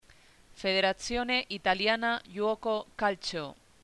NOMBRE PRONUNCIACIÓN HABLANTE NATIVO HISPANOHABLANTE
Federazione Italiana Giuoco Calcio Federatsióne Italiána Yuóko Kálcho